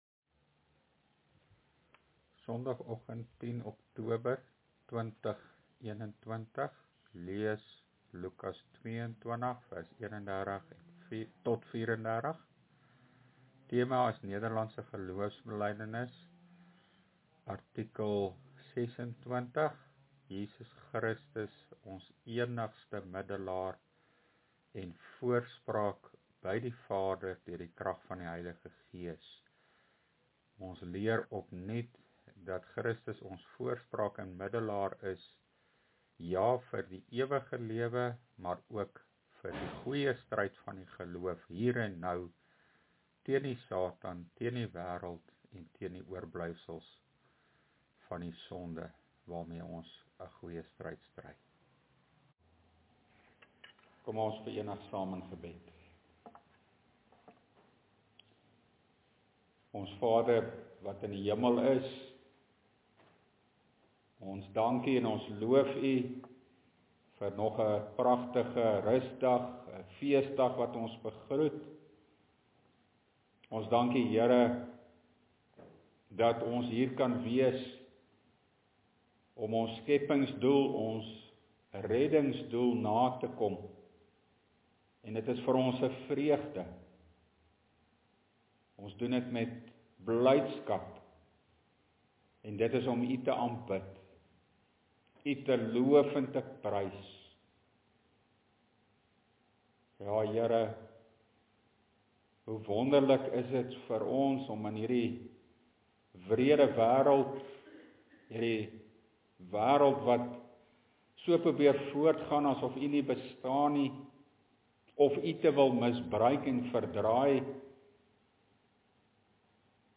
LEERPREDIKING: NGB artikel 26